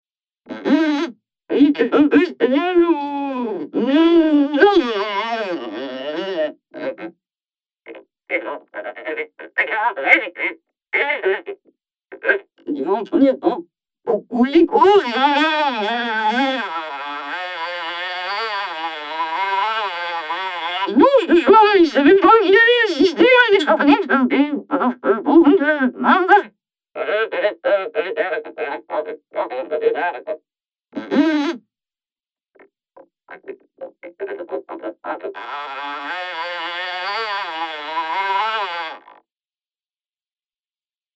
Det ska erkännas att kymat kan låta extremt bra och galet om man kan hantera det. Hade iaf roligt med en envelope follower och vocoder, hittade lite fransk radio och lät amplituden styra pitchen:
Kyma_AmpFollower+Vocoder.mp3